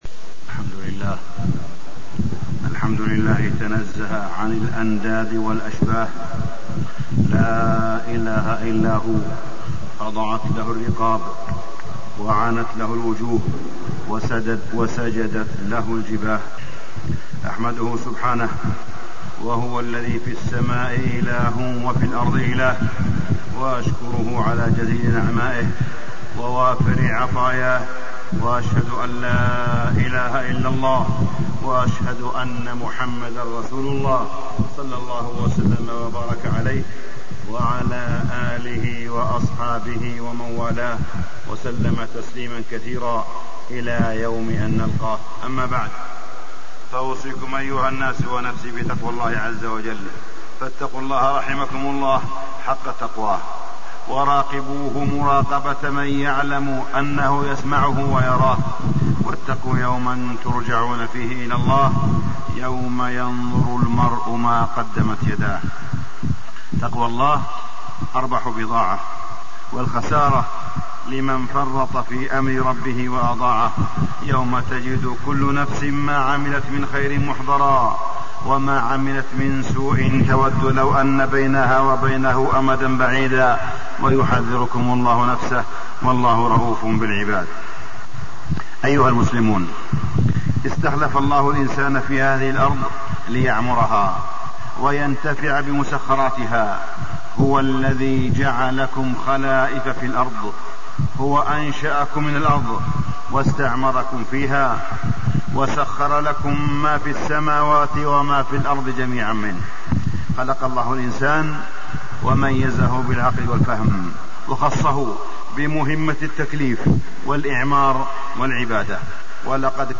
تاريخ النشر ١٥ شوال ١٤٣١ هـ المكان: المسجد الحرام الشيخ: معالي الشيخ أ.د. صالح بن عبدالله بن حميد معالي الشيخ أ.د. صالح بن عبدالله بن حميد البيئة الصالحة معاش لإنسان